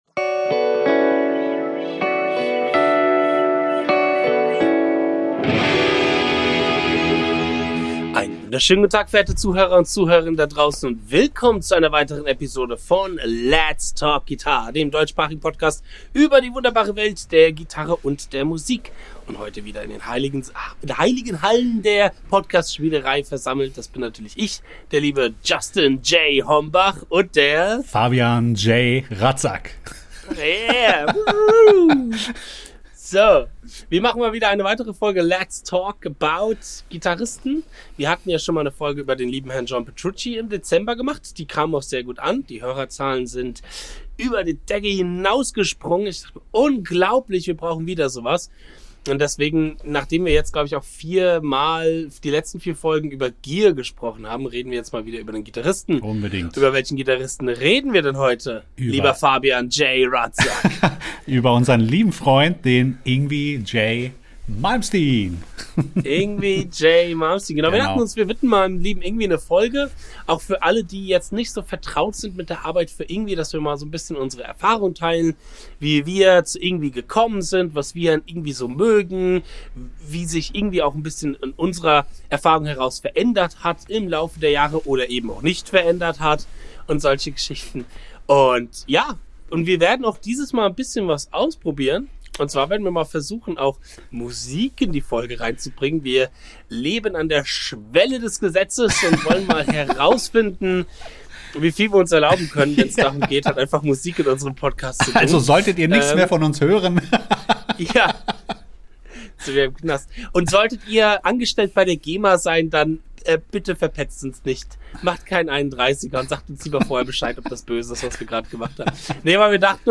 Dieses Mal sogar mit Klangbeispielen!